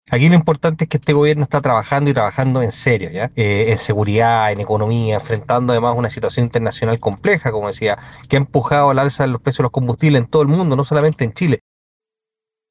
Desde el oficialismo, el diputado republicano Agustín Romero llamó a cerrar la polémica y enfocarse en la agenda del Ejecutivo.